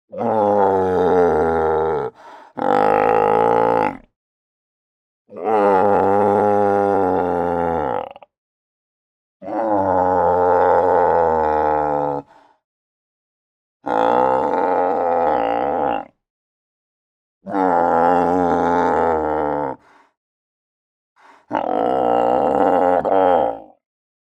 На этой странице собраны разнообразные звуки морских львов – от их громкого рычания до игривого плеска в воде.
Грубый рык морского льва